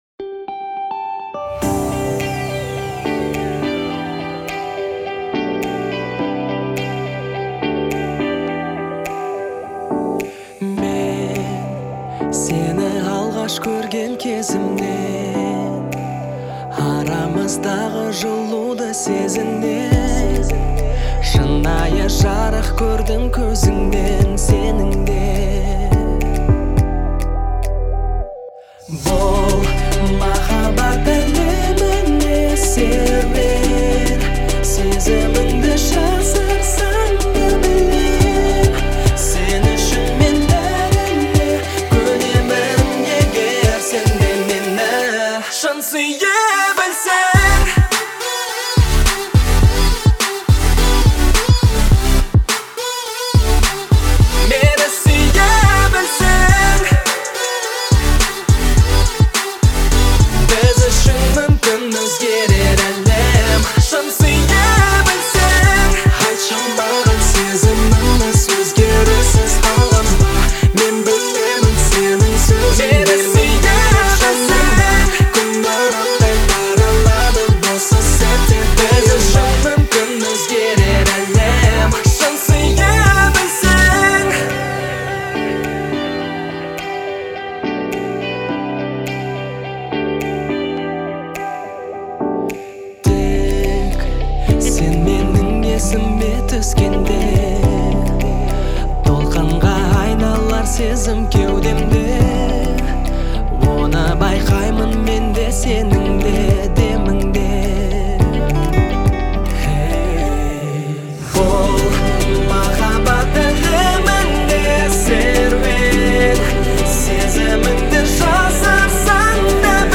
который относится к жанру поп.
создавая атмосферу тепла и нежности.